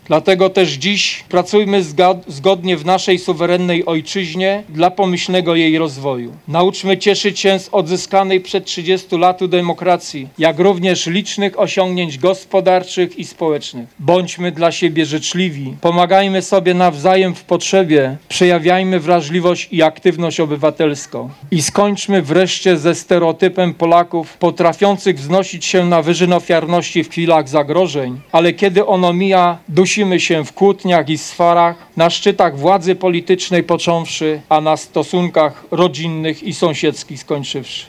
Po mszy odbyły się uroczystości patriotyczne.
Wacław Strażewicz, starosta giżycki: